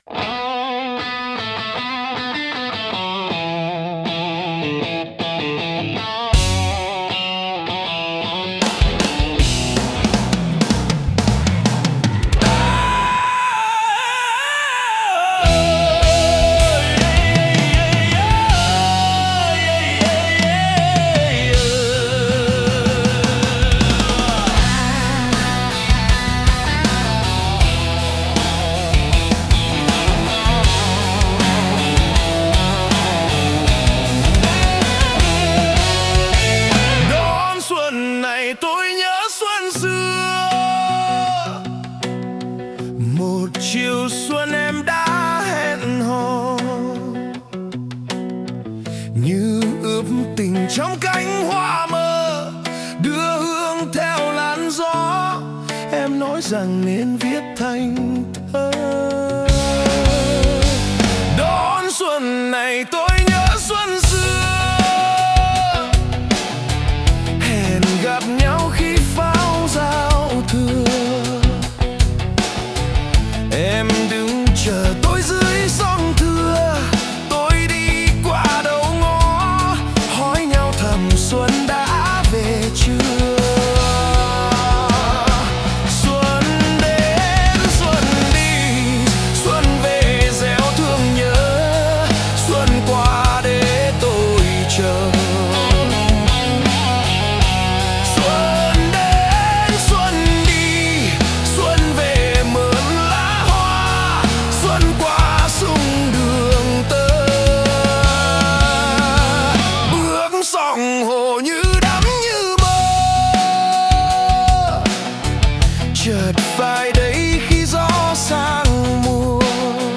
Nhạc Rock Việt Cover HayRelax